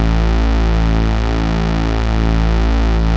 HARD SQUARE2.wav